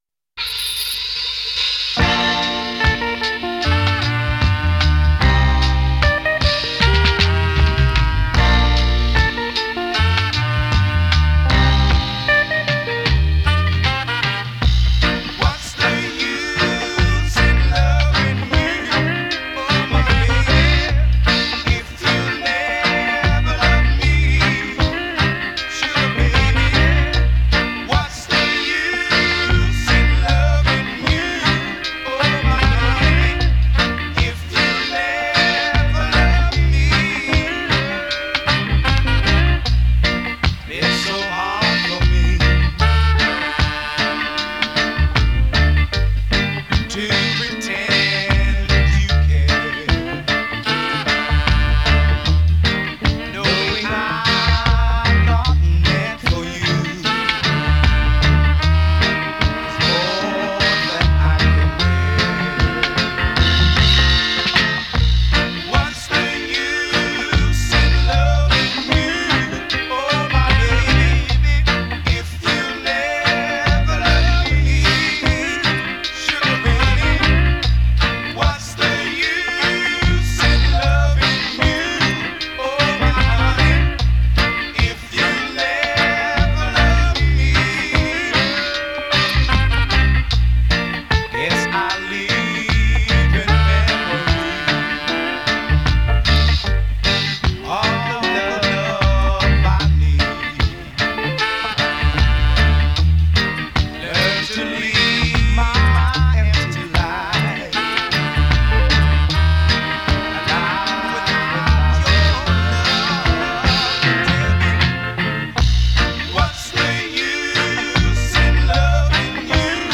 the unmistakable sweet sound of vocalist
at the Black Ark